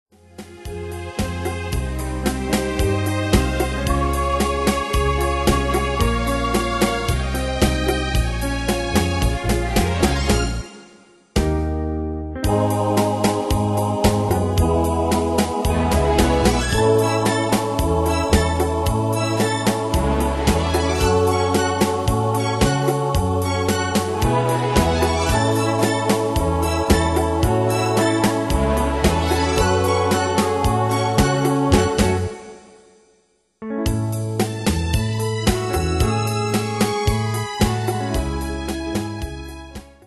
Pro Backing Tracks